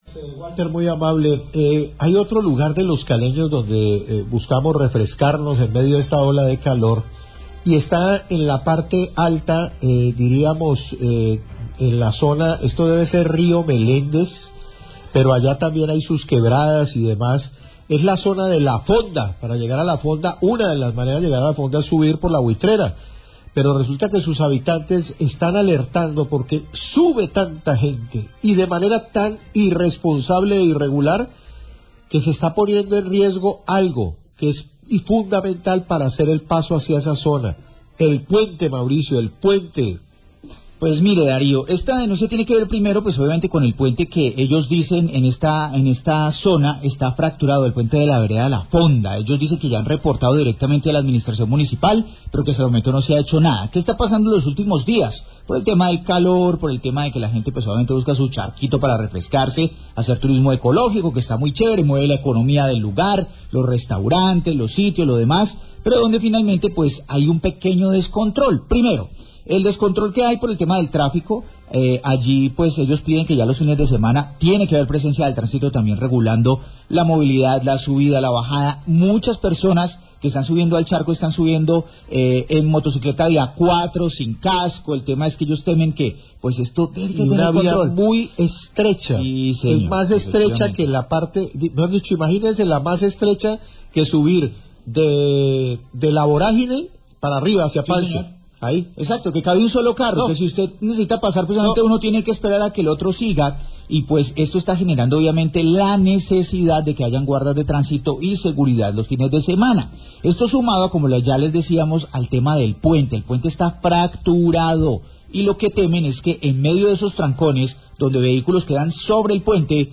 Habitante cgto La Fonda alerta por fractura puente del sector y alto flujo turistas
Radio